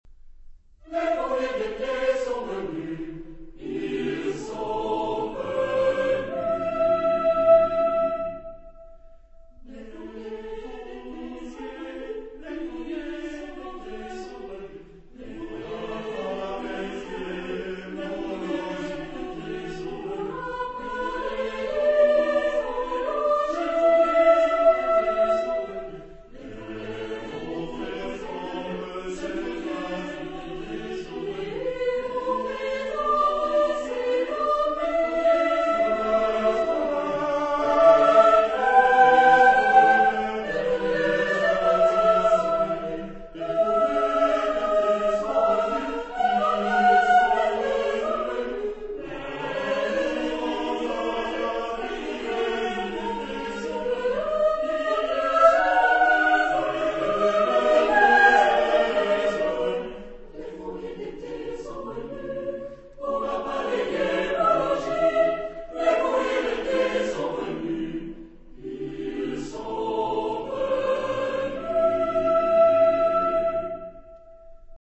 Genre-Style-Forme : contemporain ; Chanson ; Profane
Type de choeur : SATB  (4 voix mixtes )